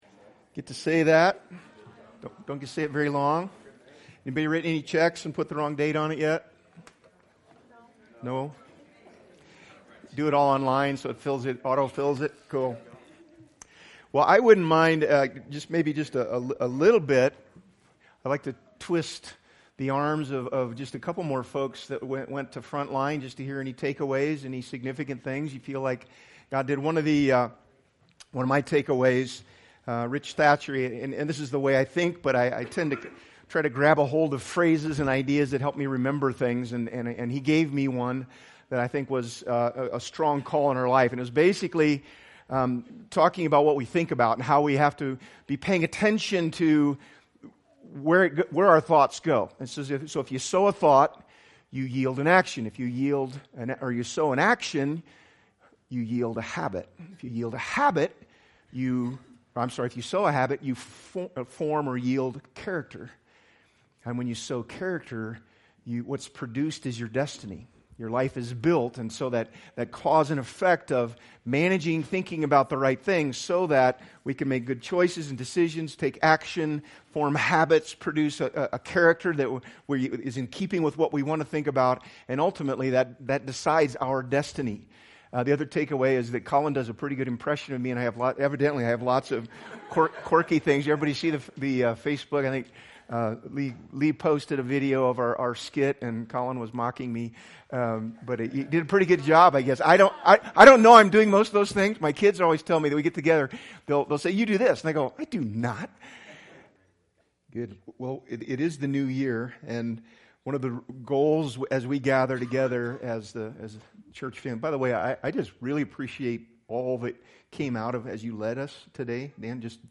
BG Archives Service Type: Sunday Series